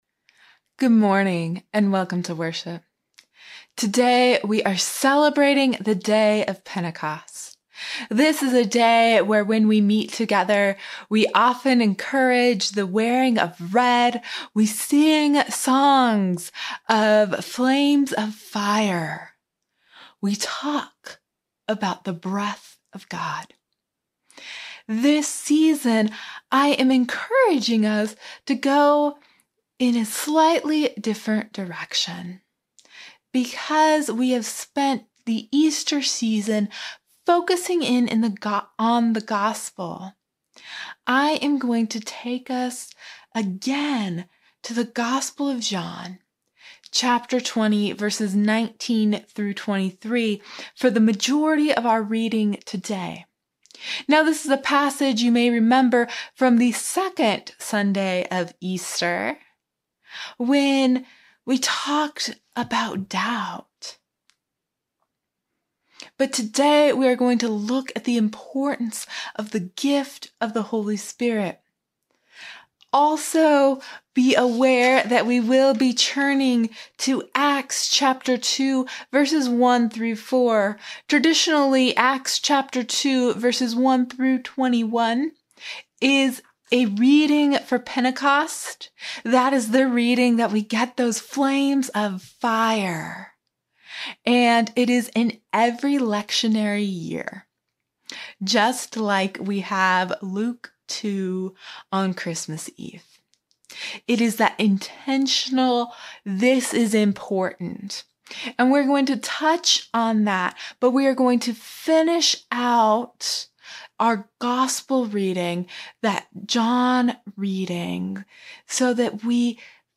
May-31-Sermon-Audio.mp3